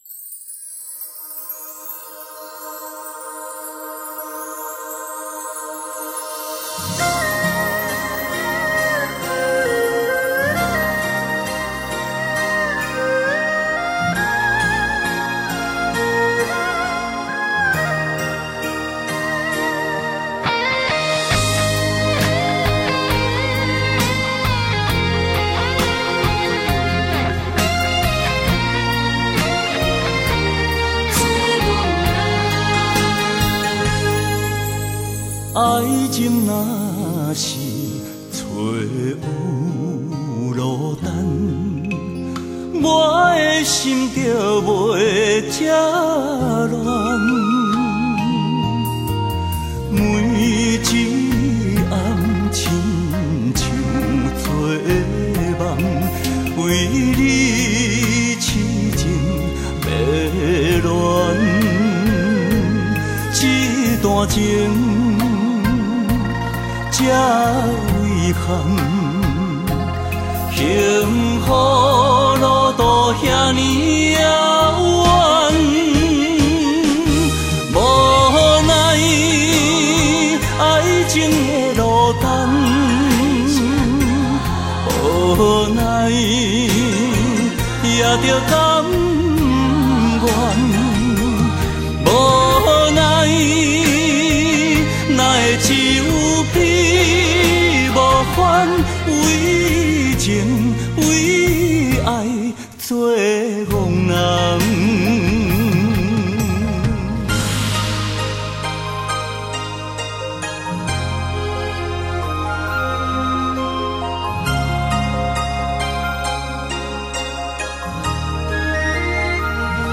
他独树一格的演唱风格，深受日本演歌影响，歌唱特色明显、
稳定性佳的演歌力道、再加上男性的特有哭腔，在台湾歌坛来说是不可多得的歌手。